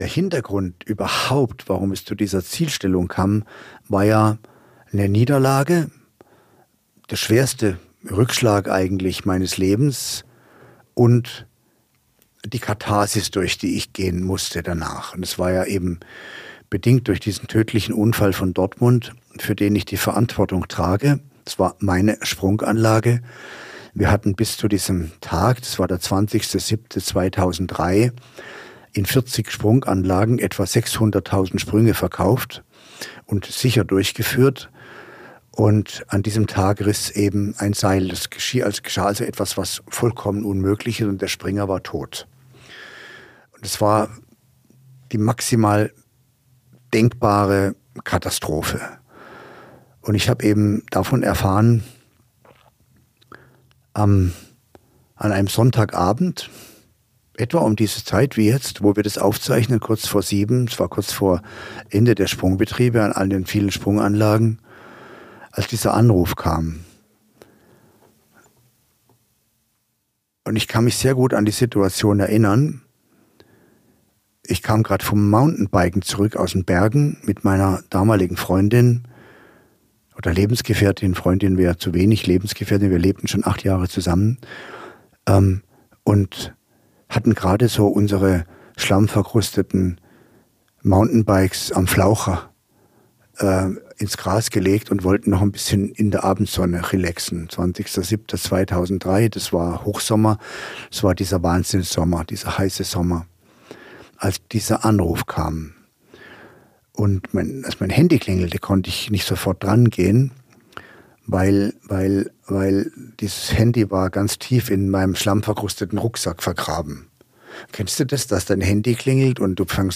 Dieses Gespräch hat tausende von Menschen bewegt und inspiriert...